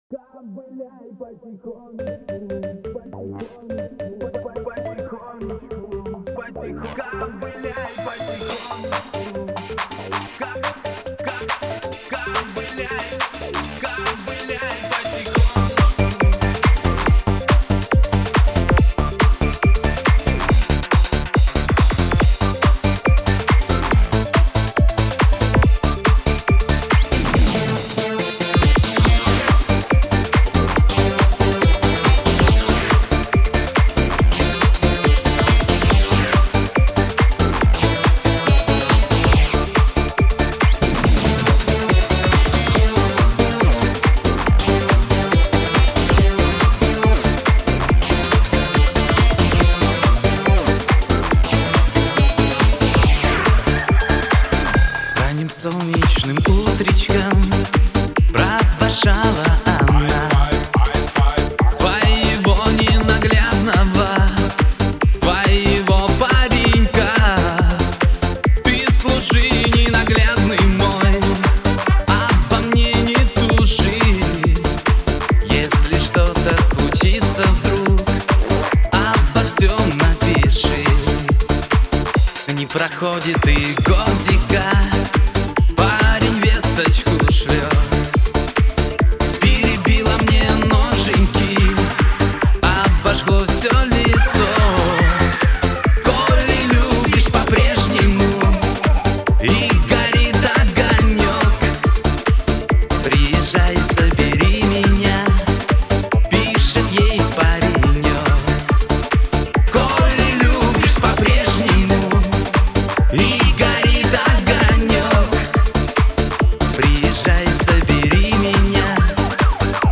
Категория: Русский Рэп/ Хип - Хоп